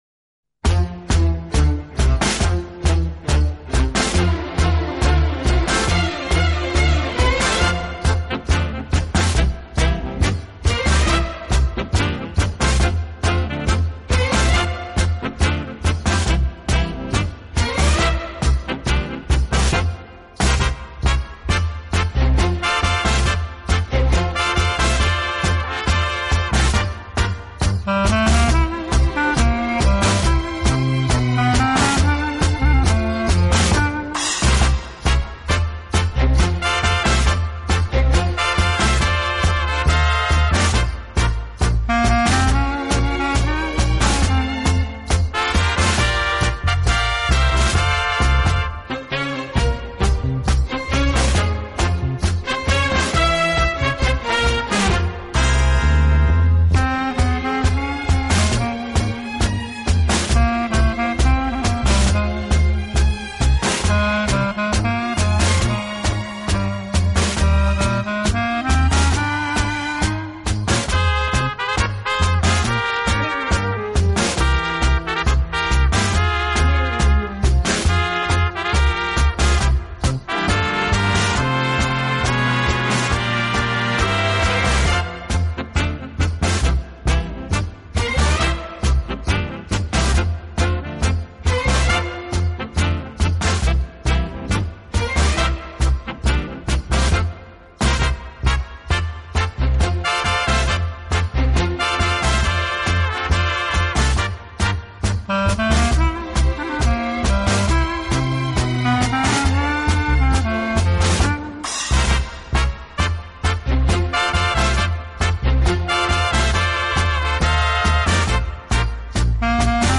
tango 34t